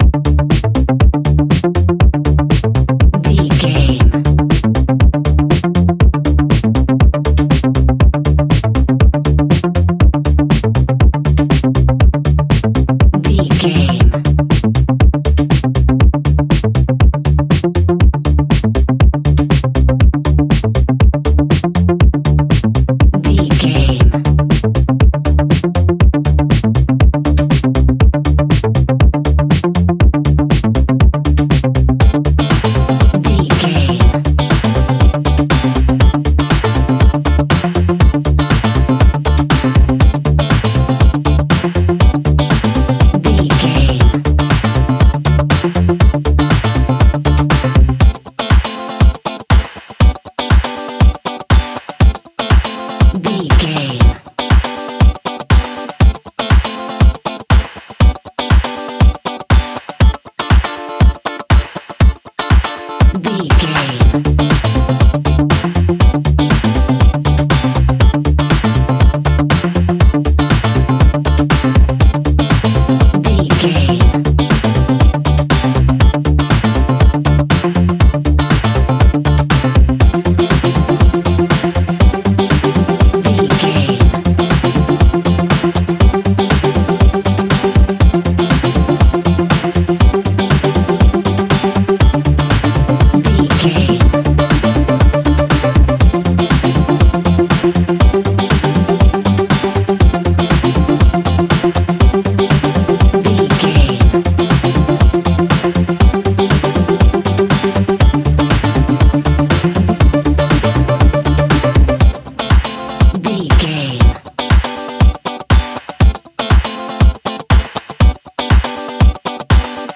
Ionian/Major
house
electro dance
synths
techno
trance
instrumentals